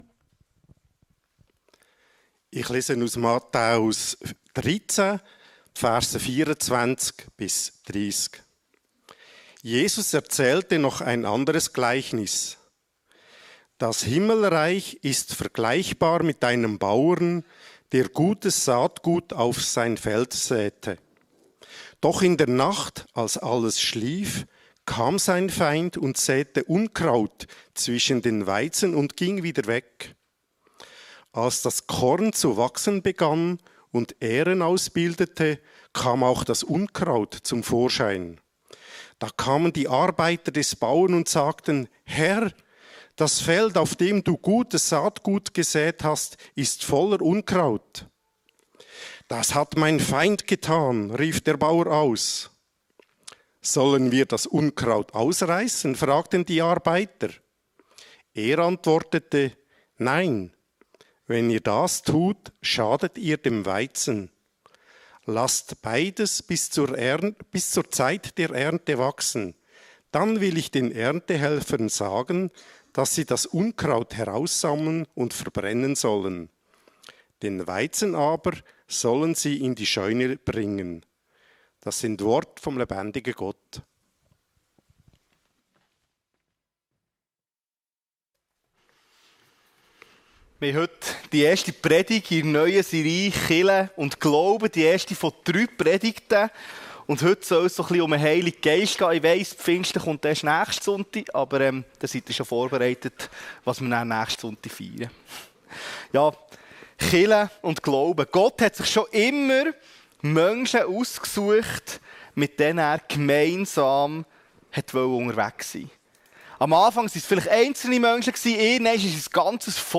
Kirche & Glaube | Der Heilige Geist - seetal chile Predigten